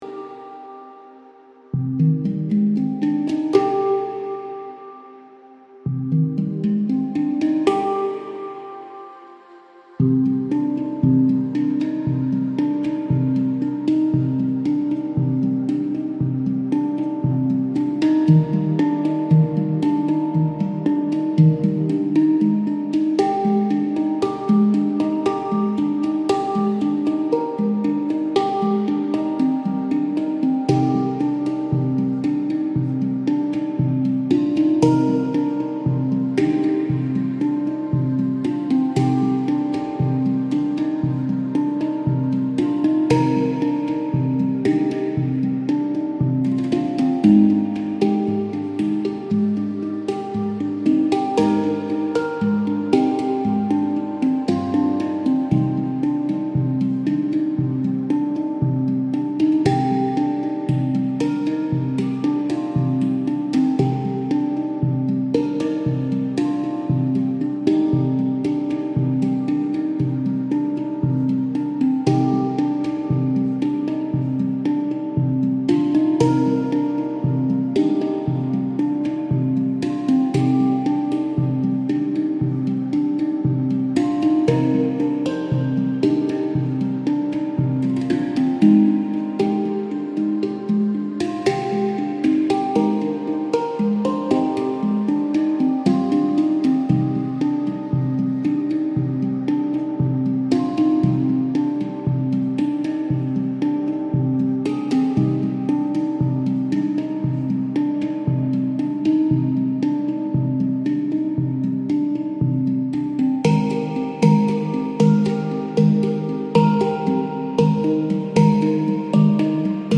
موسیقی بی کلام آرام بخش و مدیتیشین با نوازندگی ساز هنگ درام